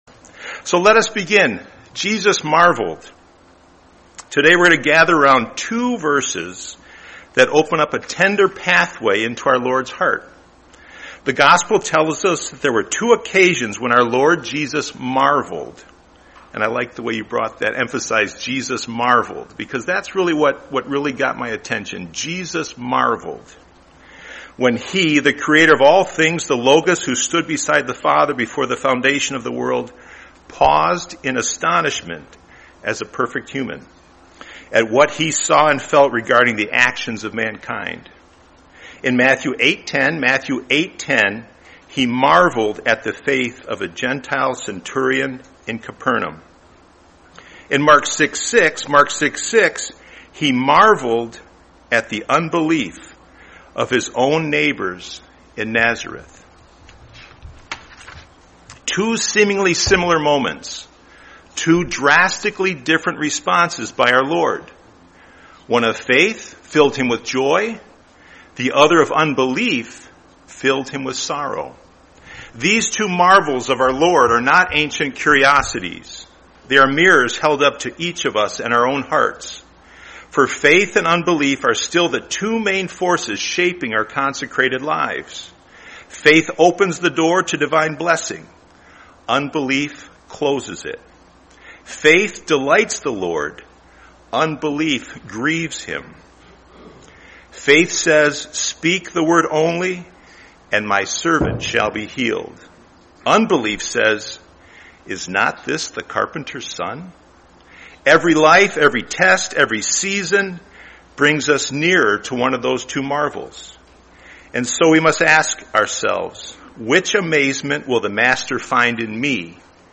Series: 2025 Orlando Convention